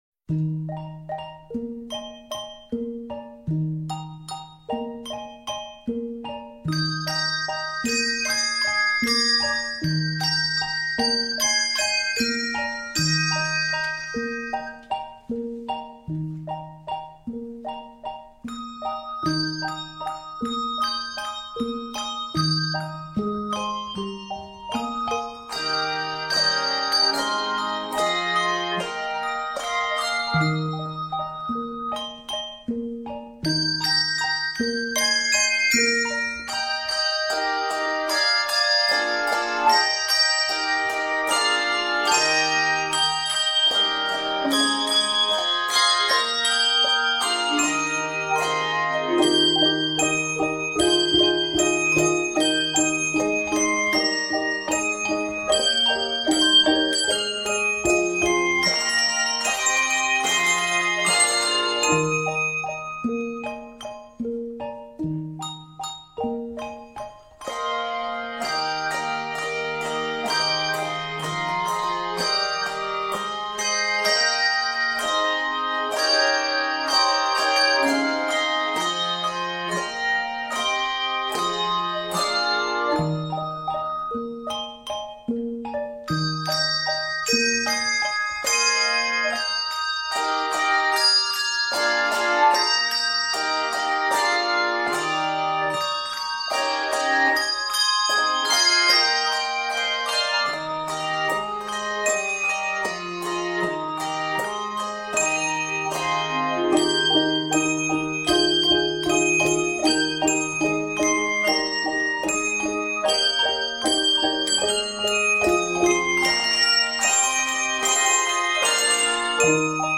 Arranged in Eb Major, it is 82 measures in length.
Octaves: 3-5